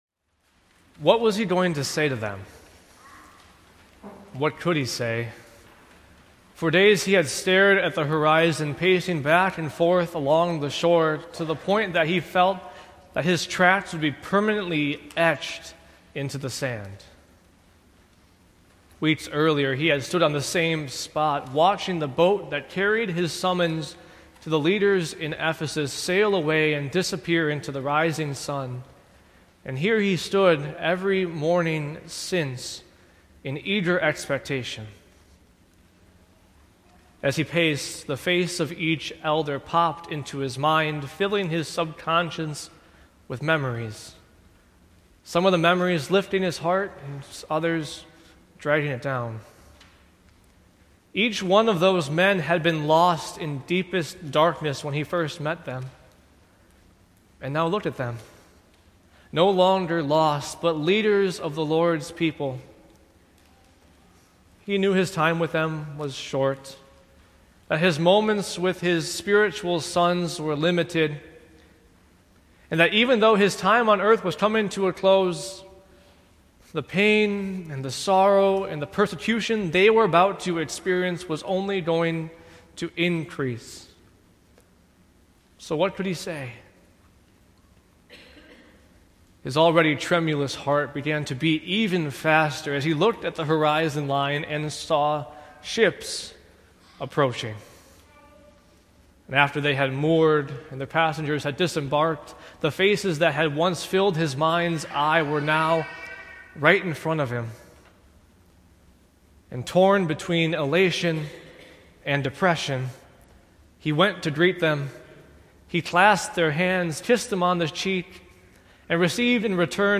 Sermons from Faith Evangelical Lutheran Church (WELS) in Antioch, IL